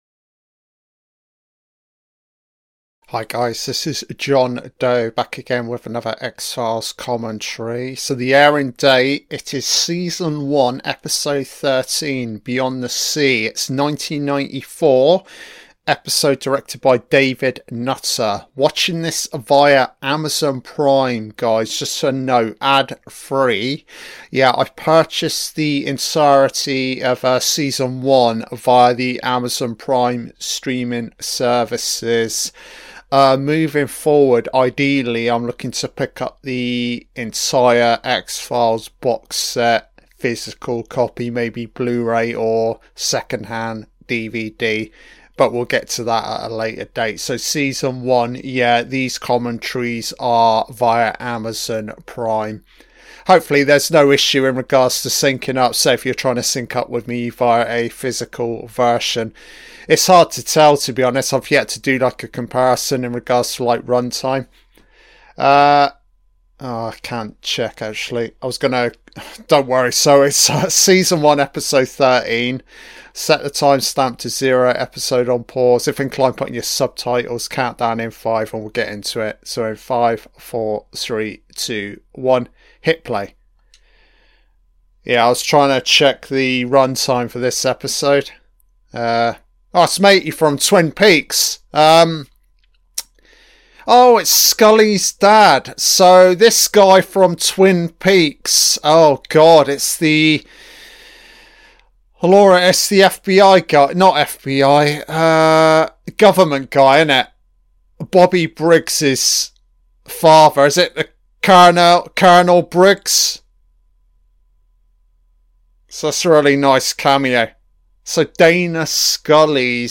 Film Fanatic Commentaries - The X-Files (1994) Beyond the Sea - TV Fanatic Commentary - Season 7